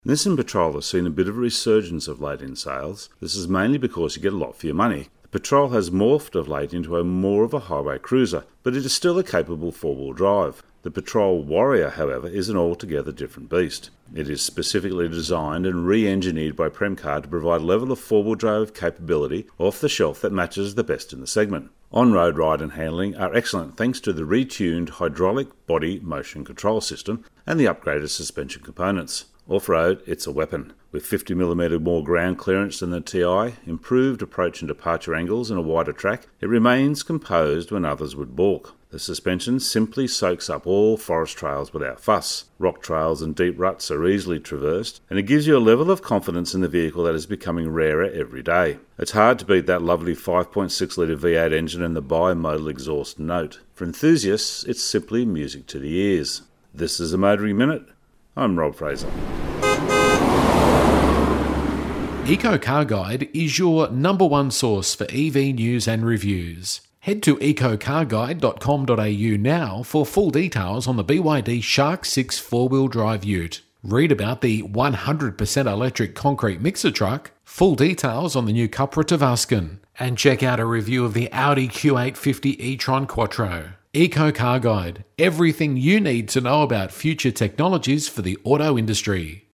Motoring Minute is heard around Australia every day on over 100 radio channels.